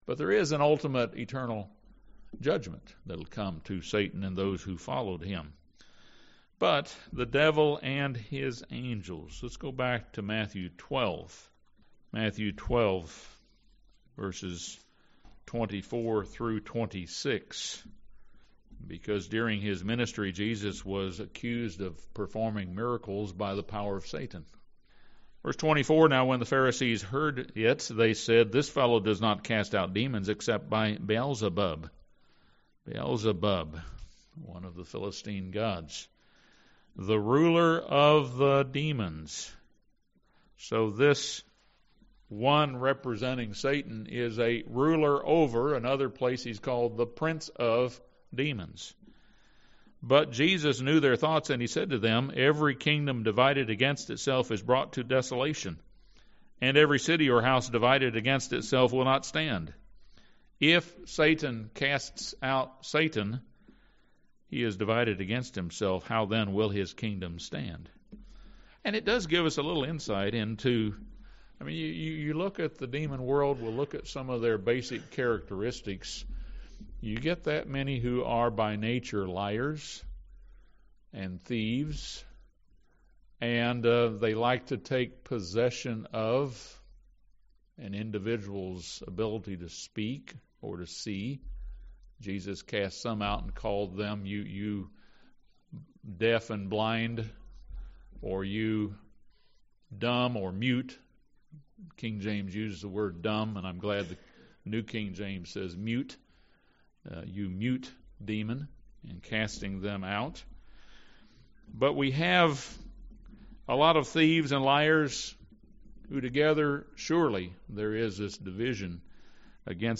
This sermon offers several points for resisting the devil and his cohorts.